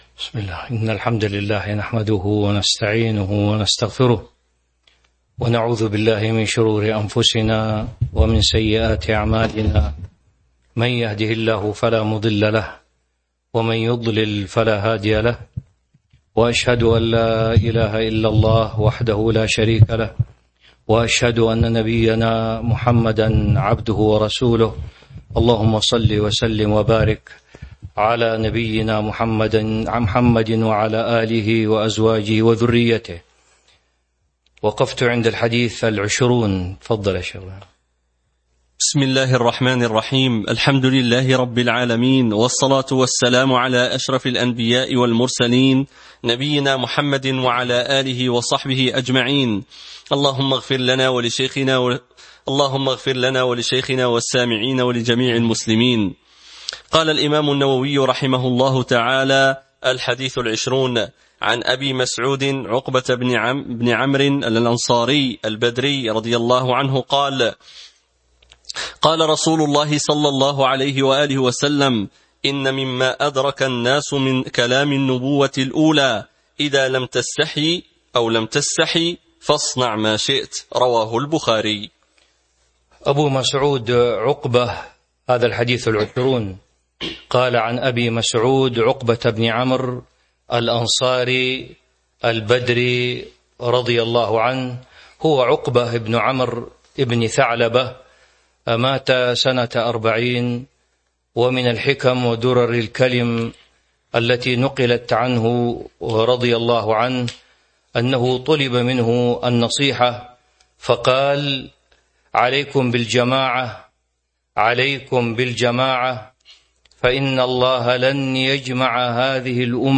تاريخ النشر ١ ذو القعدة ١٤٤٢ هـ المكان: المسجد النبوي الشيخ